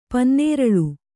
♪ pannēraḷu